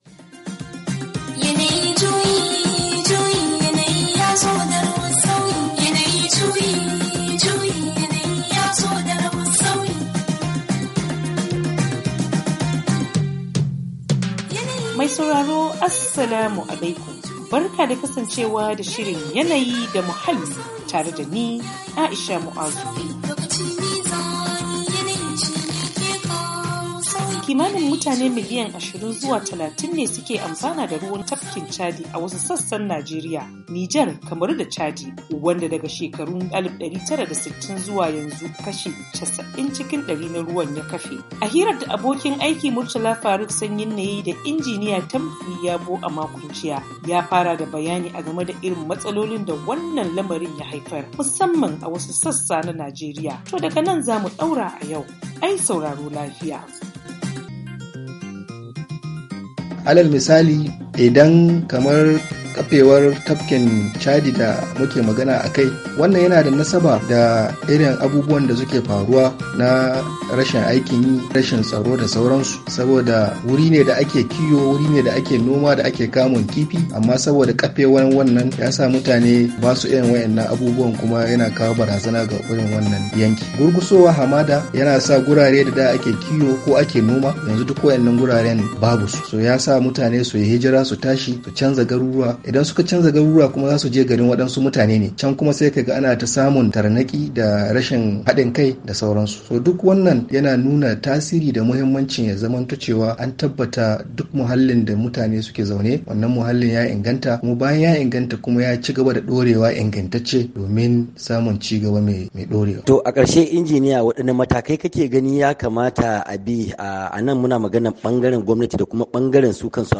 YANAYI DA MUHALLI: Ci gaban tattaunawa